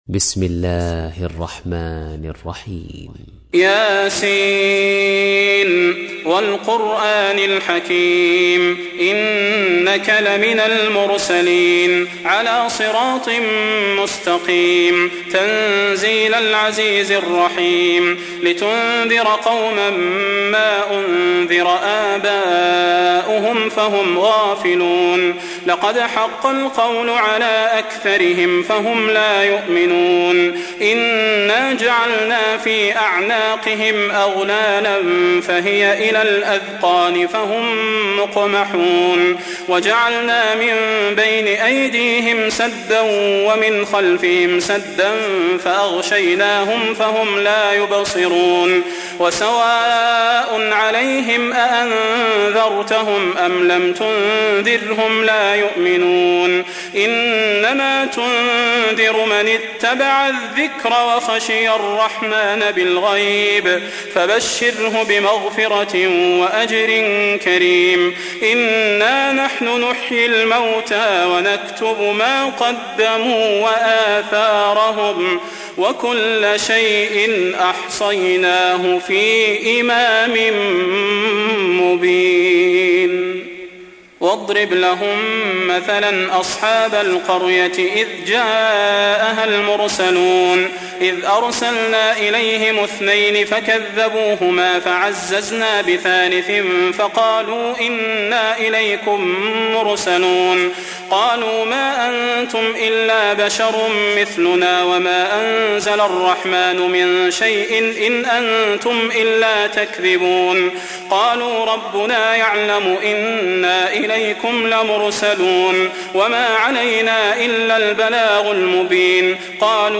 تحميل : 36. سورة يس / القارئ صلاح البدير / القرآن الكريم / موقع يا حسين